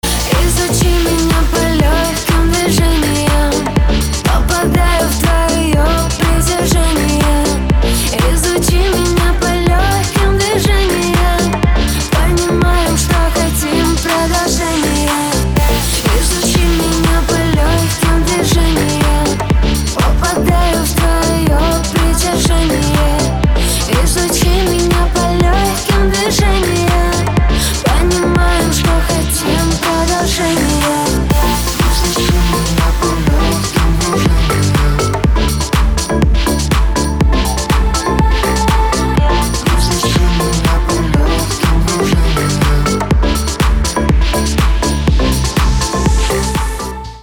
• Качество: 320, Stereo
поп
женский вокал
deep house
dance
Electronic
спокойные
club
чувственные